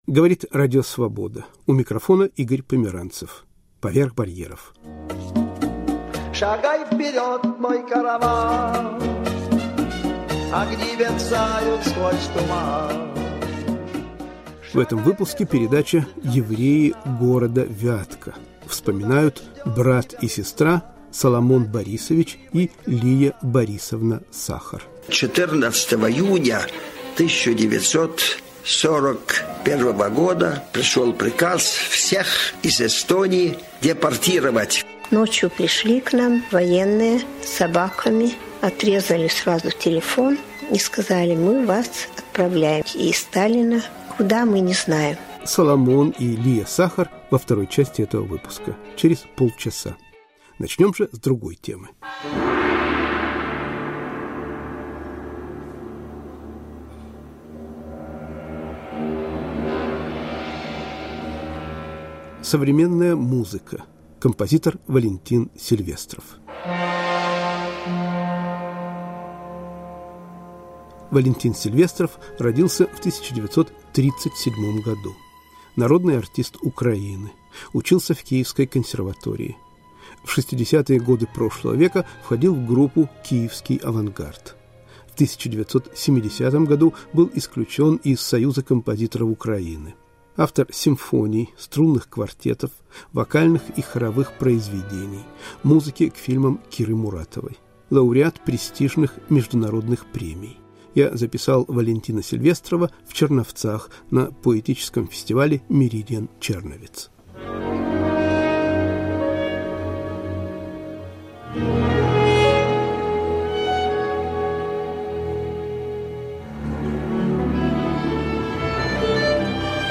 Монологи композитора и отрывки из произведений *** «Евреи города Вятки».